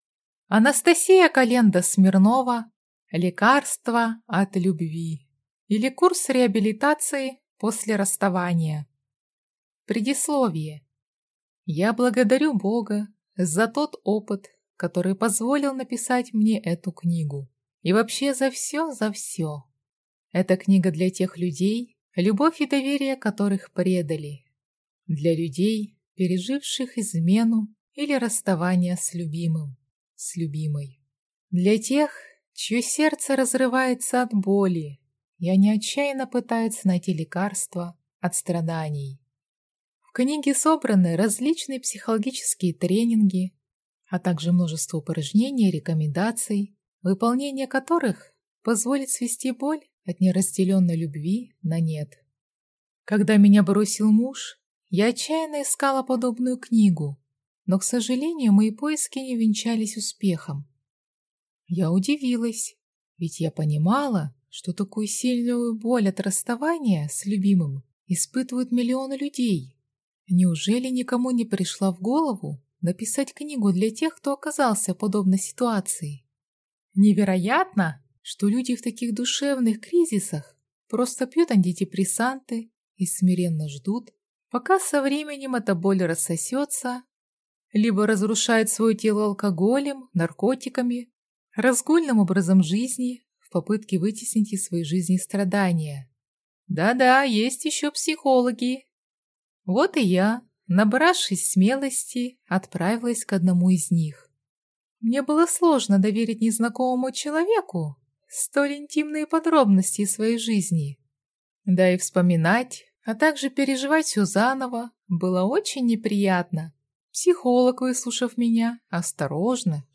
Аудиокнига Лекарство от любви, или Курс реабилитации после расставания | Библиотека аудиокниг